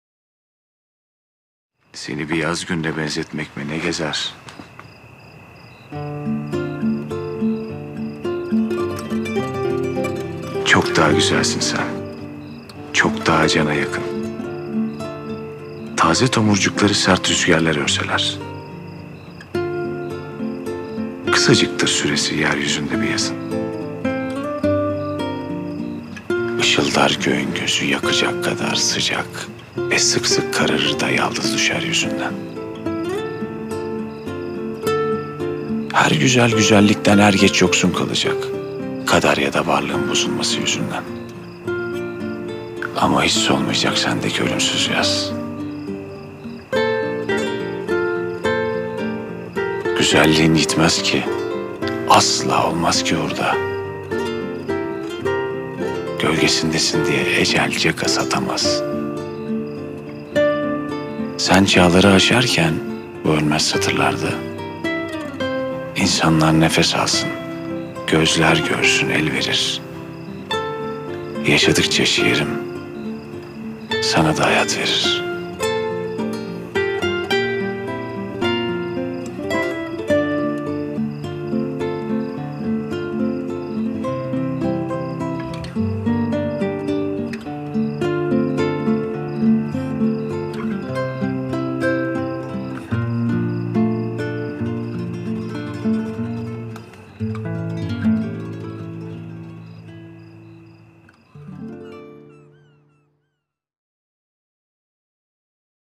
dizi müziği, duygusal hüzünlü rahatlatıcı şarkı.